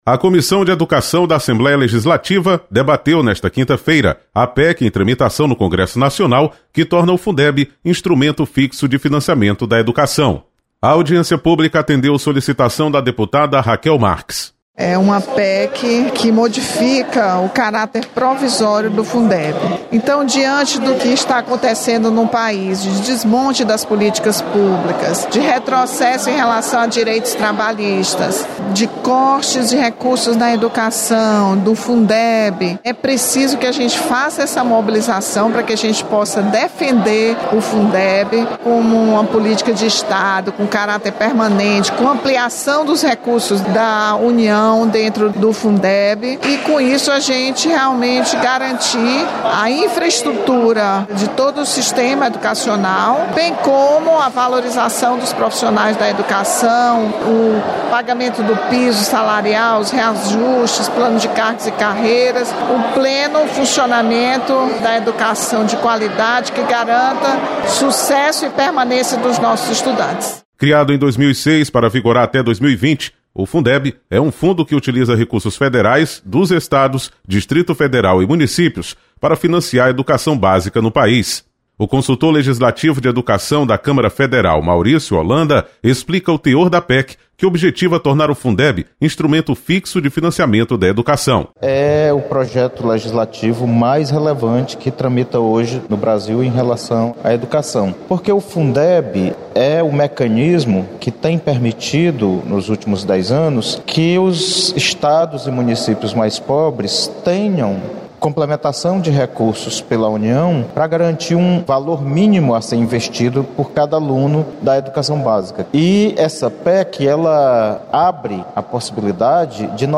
Você está aqui: Início Comunicação Rádio FM Assembleia Notícias Audiencia